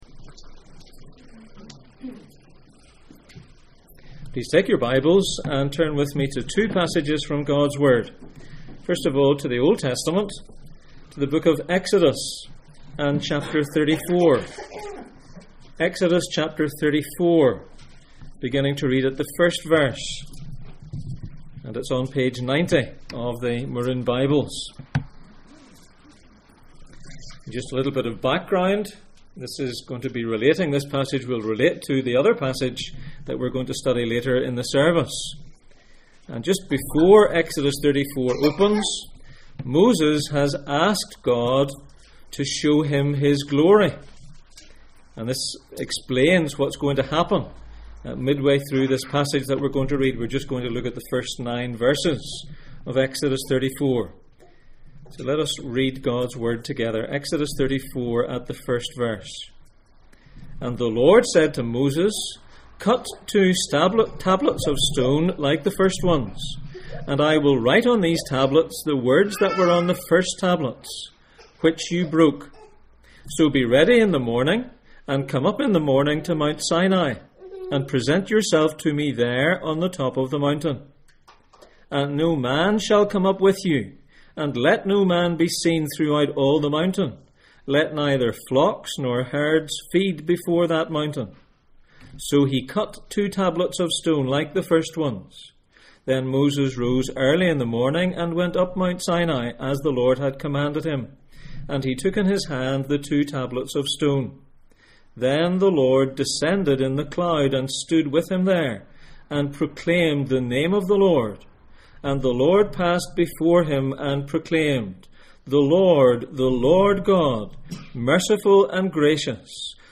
Exodus 34:1-9 Service Type: Sunday Morning %todo_render% « The Word becomes flesh Why is Jesus’s Transfiguration here?